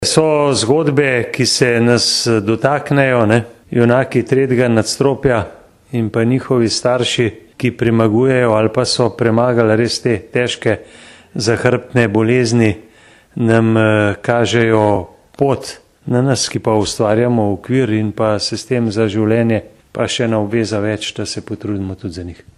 izjava_zupanobcinetrzicmag.borutsajovicozlatihpentljicah.mp3 (548kB)